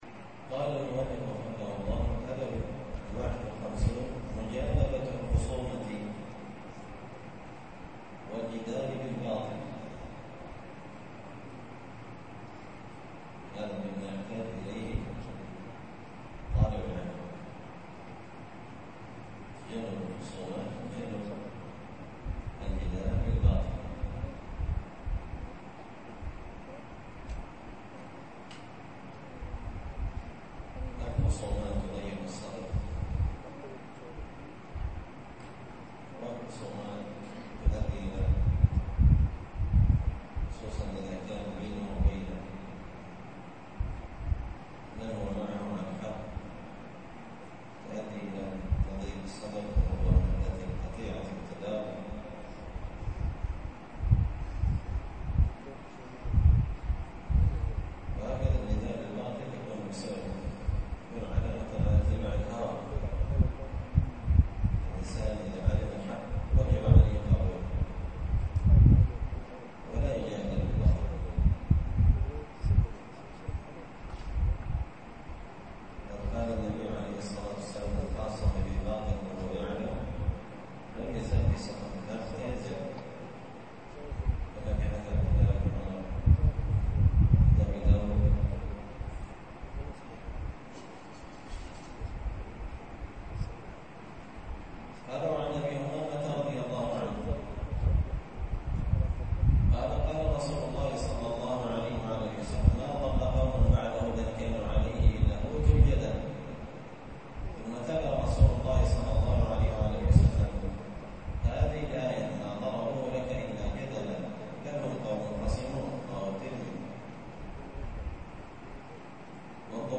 الدرس التاسع والخمسون (59) الأدب الحادي والخمسون مجانبة الخصومة والجدال في الباطل